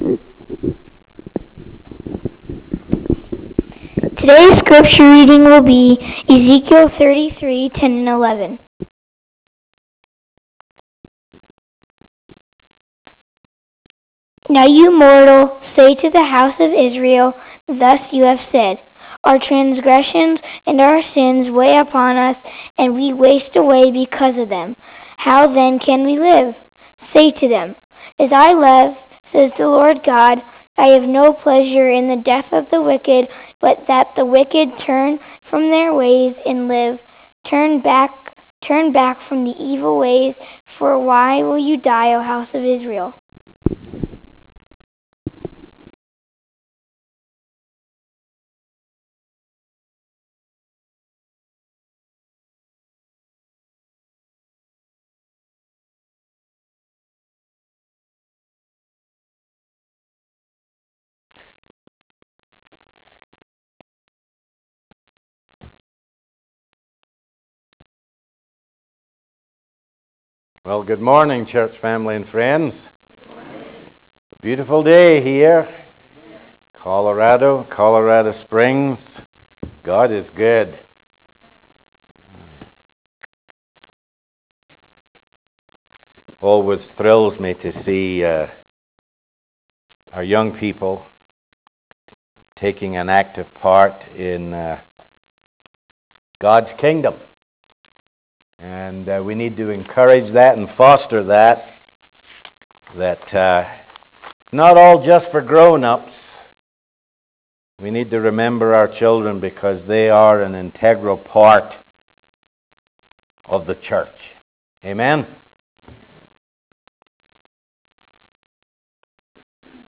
10-14-12 AM Sermon